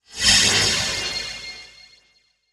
MAGIC SPELL Metallic Fusion Fast Fade In Out (stereo).wav